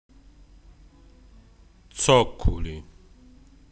Alcuni vocaboli del dialetto Ruffanese.